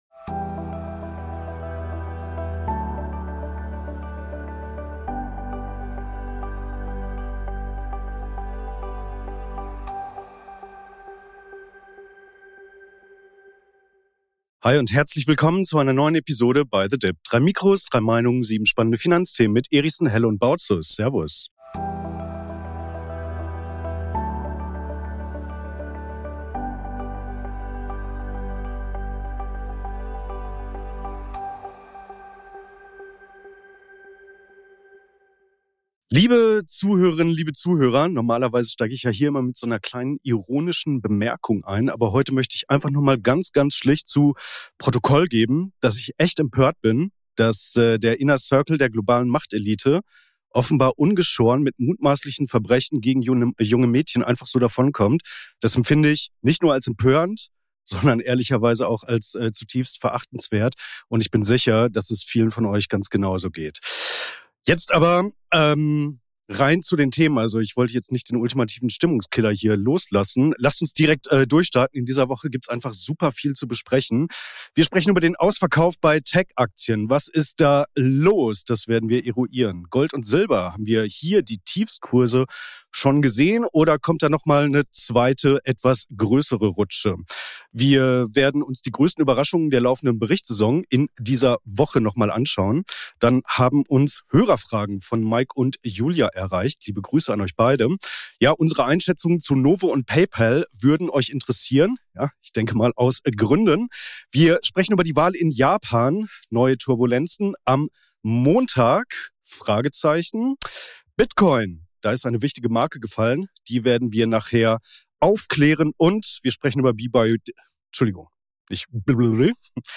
Auch diese Woche begrüßen wir euch unter dem Motto „3 Mikrofone, 3 Meinungen“ zu den folgenden Themen in dieser Ausgabe: Ausverkauf bei Tech-Aktien: Was ist da los?